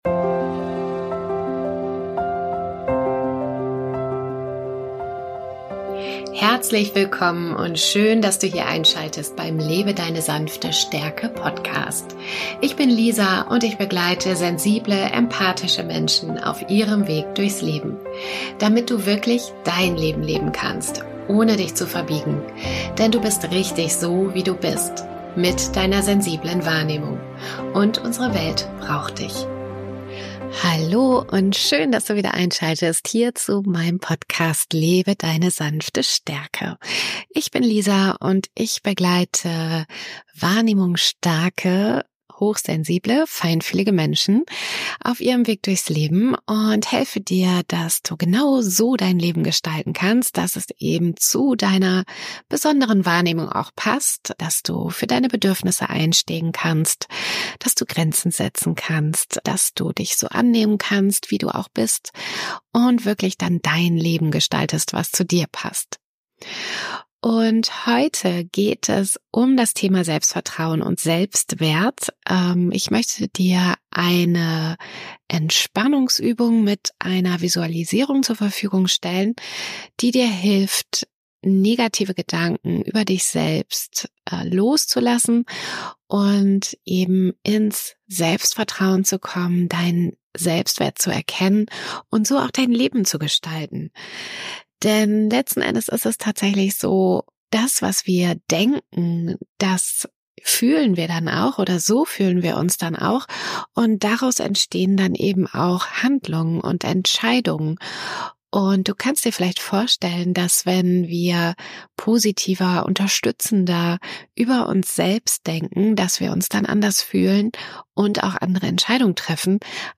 In dieser Folge bekommst Du von mir eine Entspannungsübung mit Visualisierung, die Dir dabei Hilft negative Gedanken loszulassen und Dein Selbstvertrauen zu stärken. Durch die Übung erfährst Du, wie Du Deine Gedanken lenken und positiv beeinflussen kannst.